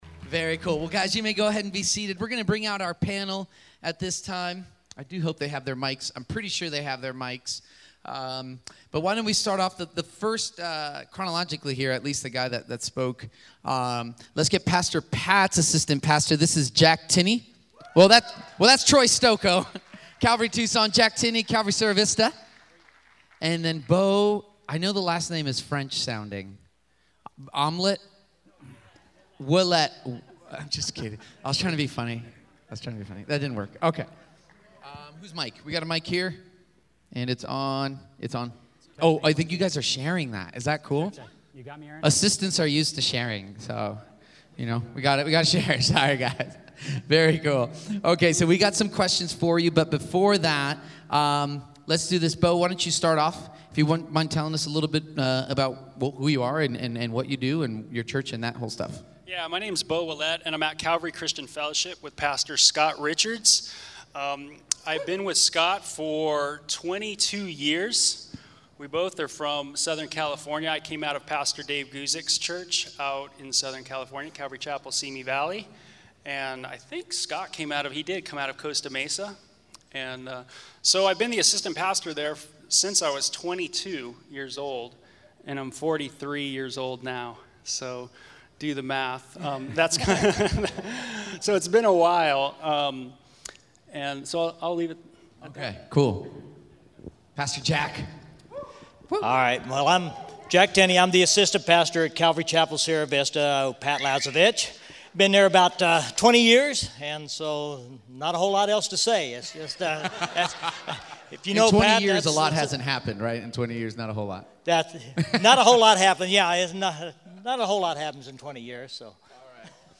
Jesus is Greater Than: Assistant Pastors Workshop Mar 8, 2016 · undefined Listen to Assistant Pastors Workshop at the 2016 SW Pastors and Leaders Conference, Jesus is Greater Than.